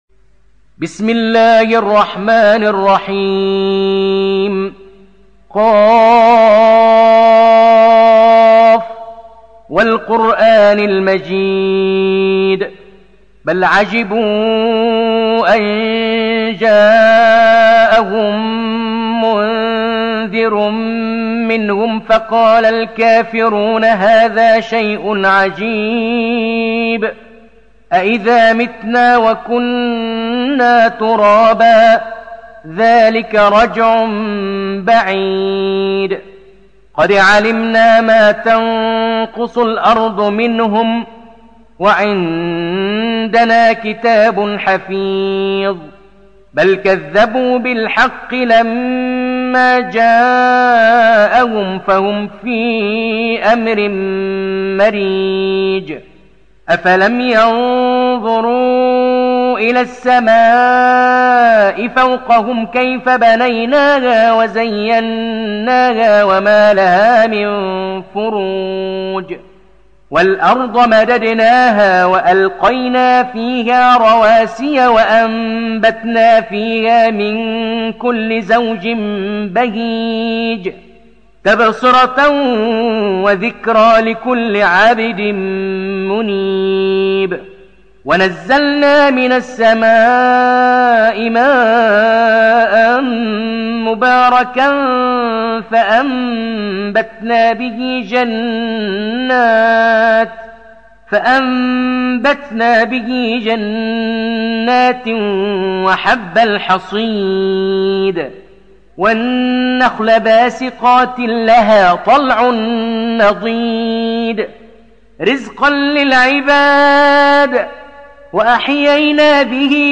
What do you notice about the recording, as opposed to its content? Riwayat Hafs an Assim